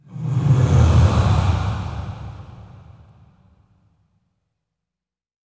SIGHS 1   -R.wav